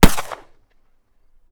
flintlock_backf.wav